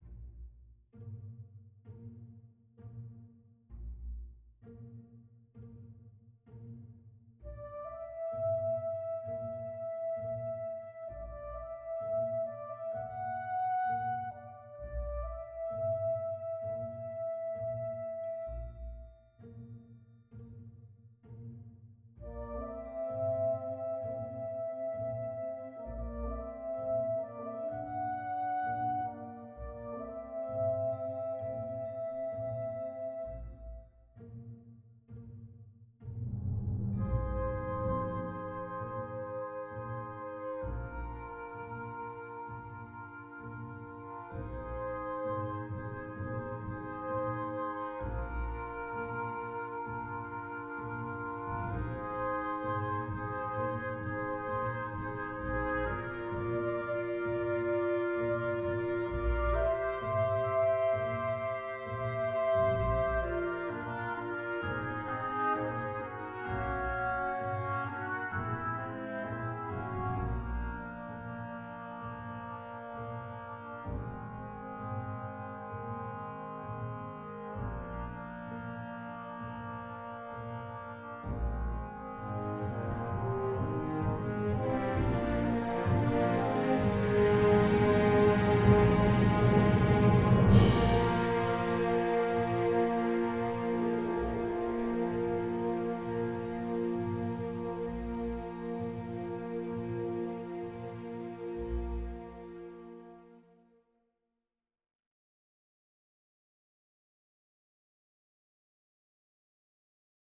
Orchestra audio (con voci sintetiche)